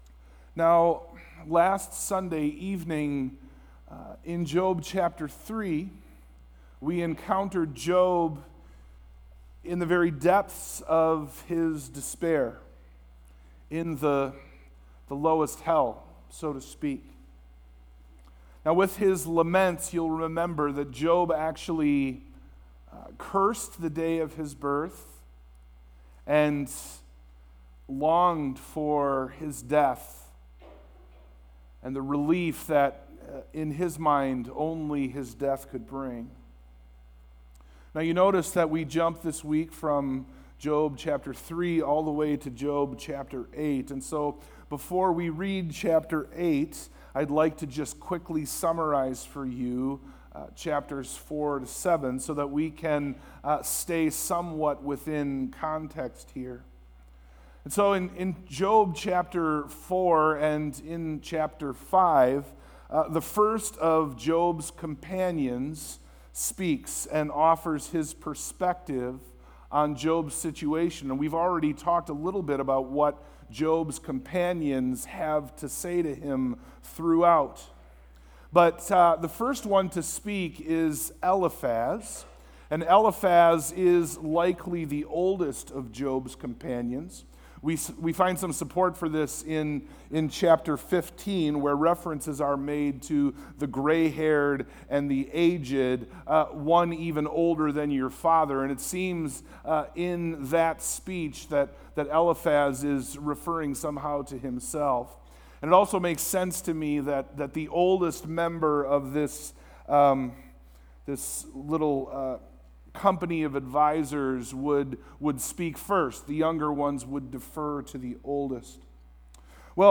Sermon+Audio+-+Born+Yesterday.mp3